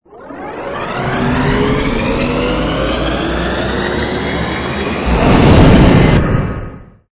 engine_li_cruise_start.wav